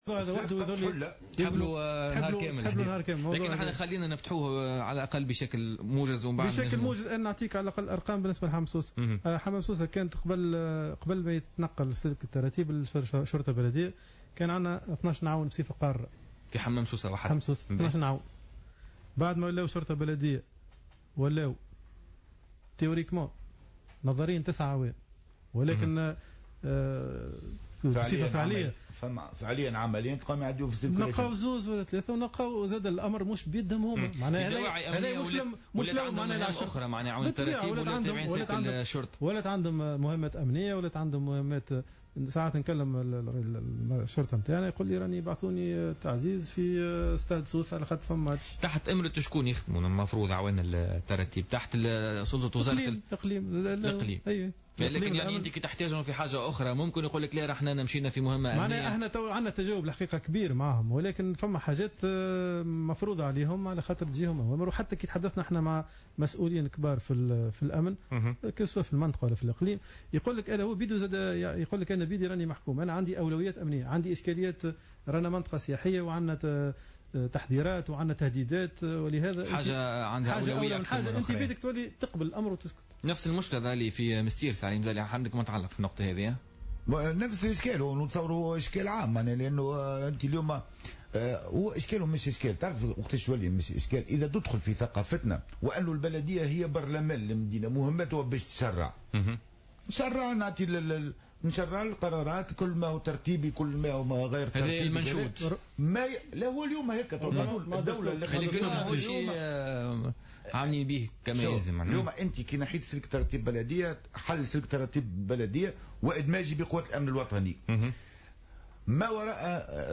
أكدّ رئيس النيابة الخصوصية بالمنستير علي مزالي ضيف بوليتيكا اليوم الجمعة 18 سبتمبر 2015 أن حل سلك أعوان التراتيب البلدية وإلحاقهم بوزارة الداخلية ساهم في تعميق أزمة نقص اليد العاملة.